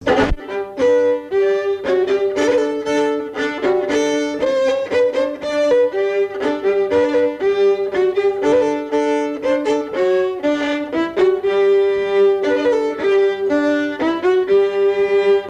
chanteur(s), chant, chanson, chansonnette
Catégorie Pièce musicale inédite